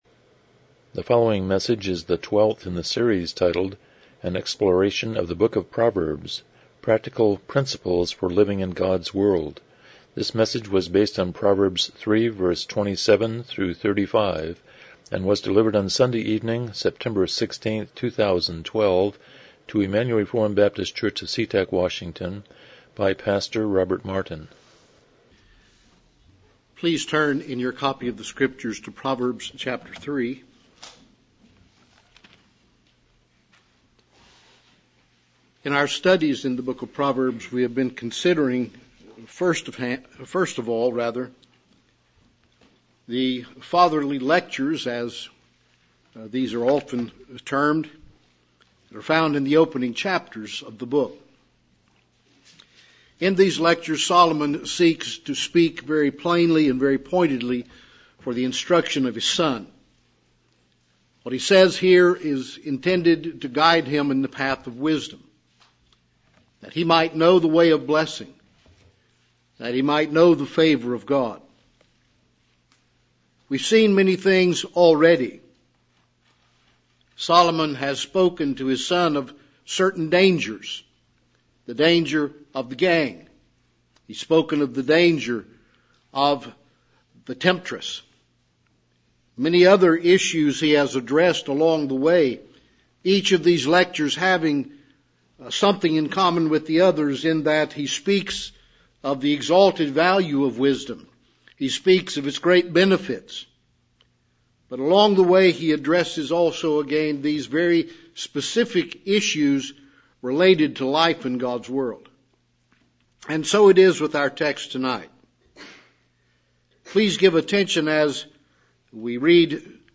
Proverbs 3:27-35 Service Type: Evening Worship « 12 Sermon and the Mount